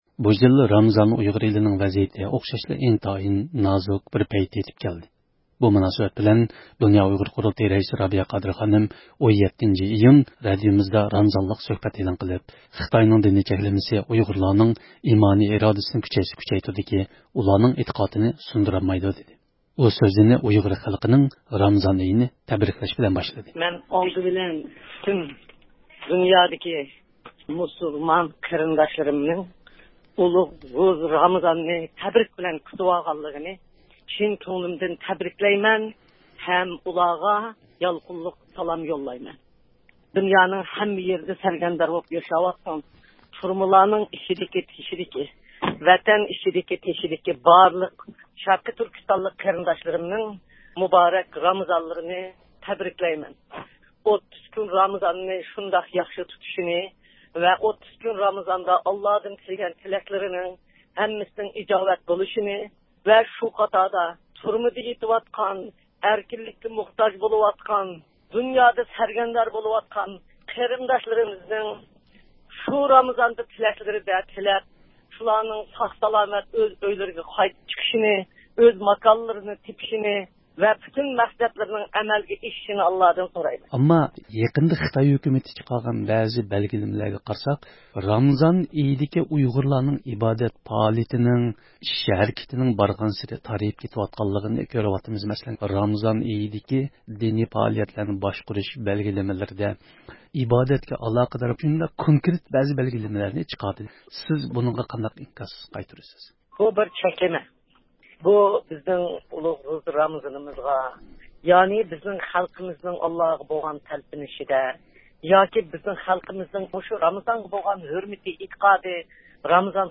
بۇ مۇناسىۋەت بىلەن رابىيە قادىر خانىم 17-ئىيۇن رادىيومىزدا رامىزانلىق سۆھبەت ئېلان قىلىپ، دىنىي چەكلىمە ئۇيغۇرلارنىڭ ئىمانى، ئىرادىسىنى كۈچەيتسە كۈچەيتىدۇكى، ئۇلارنىڭ ئېتىقادىنى سۇندۇرالمايدۇ، دېدى.